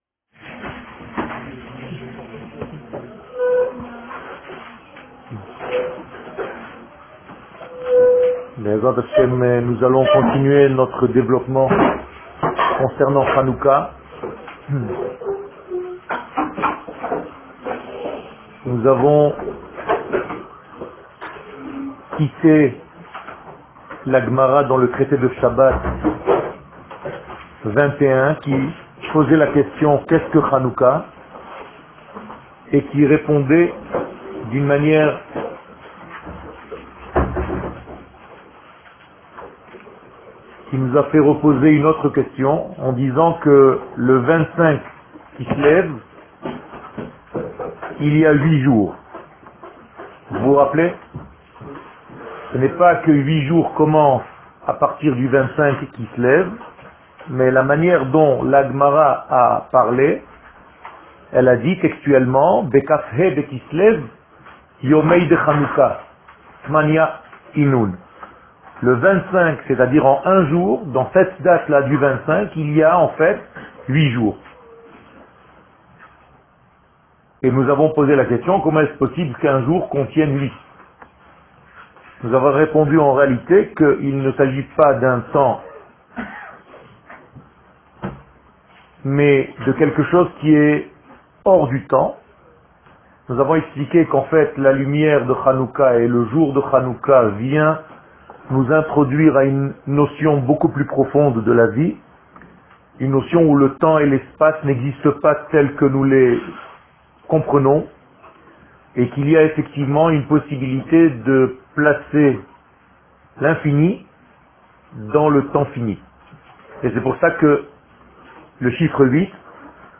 Fetes/Calendrier שיעור מ 18 דצמבר 2017 01H 00MIN הורדה בקובץ אודיו MP3 (10.3 Mo) הורדה בקובץ אודיו M4A (7.14 Mo) TAGS : Hanouka Torah et identite d'Israel שיעורים קצרים